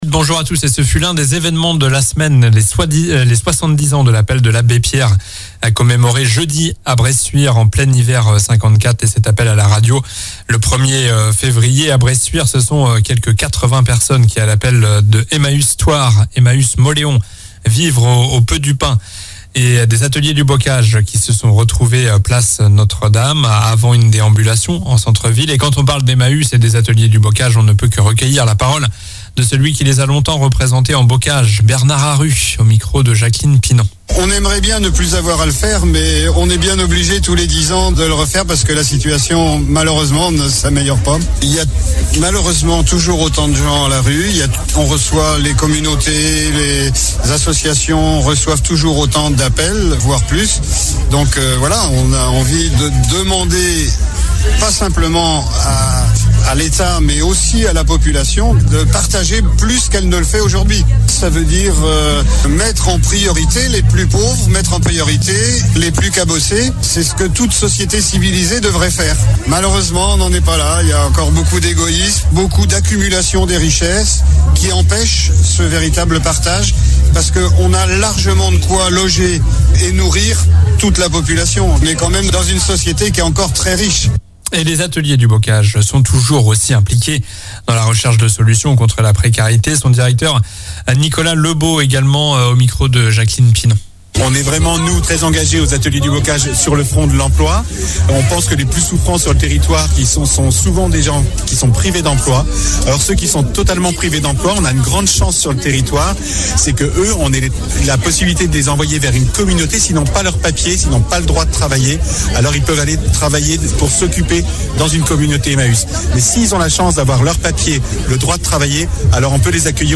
Journal du samedi 03 février (midi)